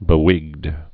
(bĭ-wĭgd)